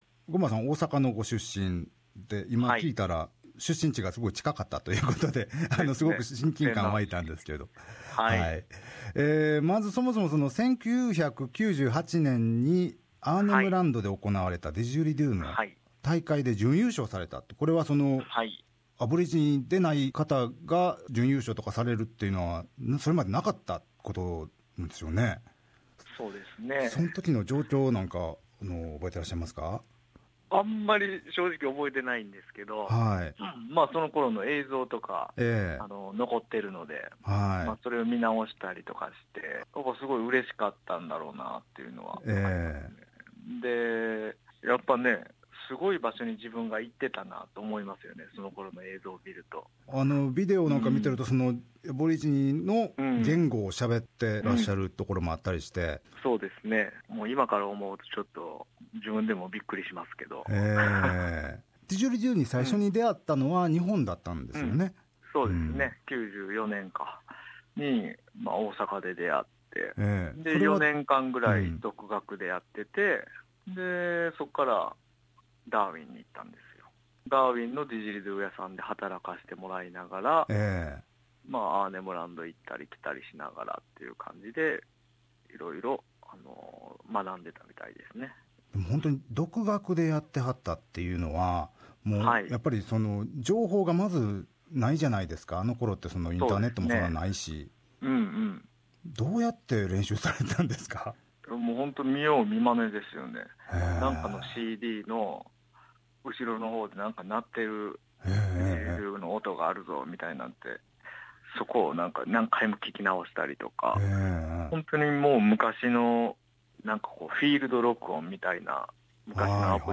The first half of this 2-part interview starts with his first encounter with didgeridoo. Aired on 16 February 2017.